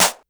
Snare_27.wav